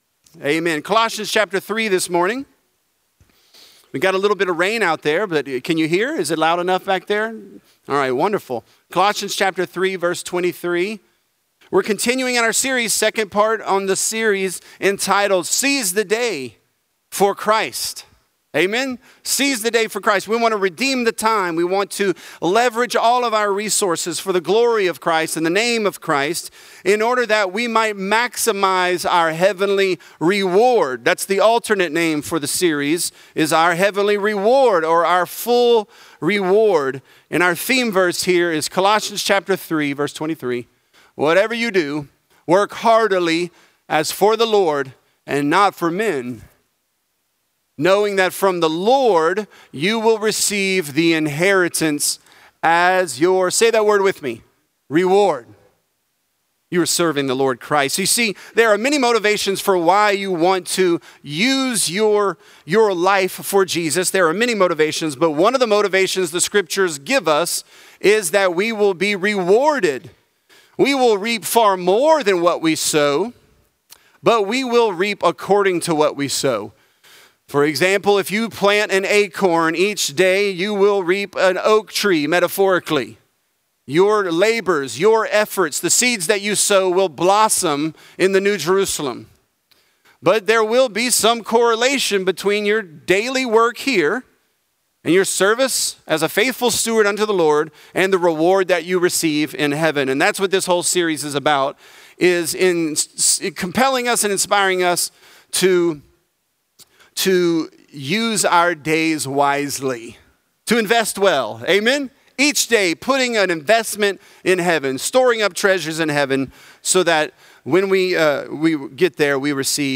Seize The Day: Love & Fellowship | Lafayette - Sermon (Colossians 3)